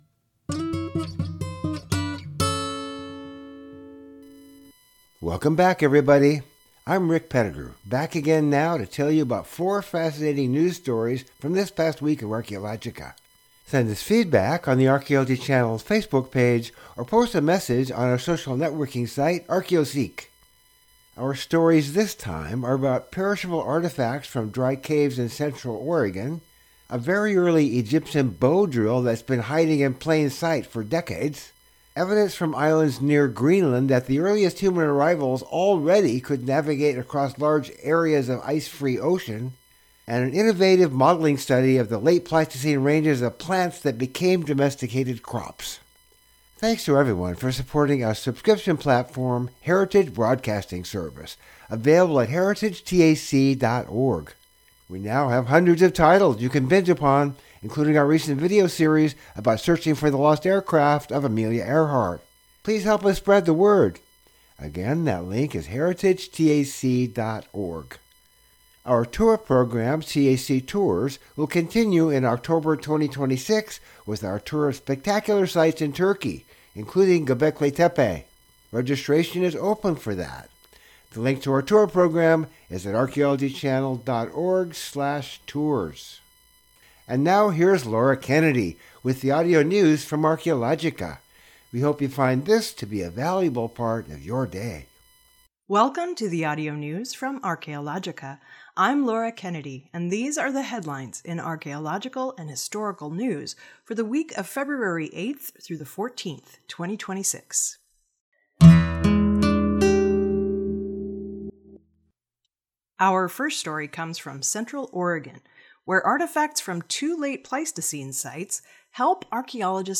Audio News from Archaeologica